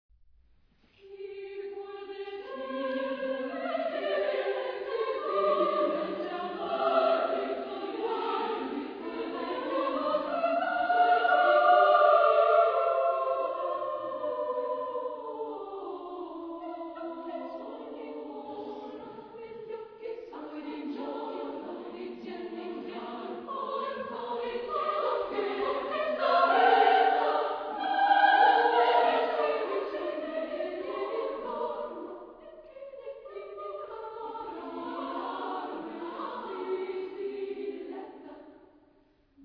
Genre-Style-Forme : Profane ; Madrigal
Type de choeur : SSA  (3 voix égales de femmes )
Tonalité : do majeur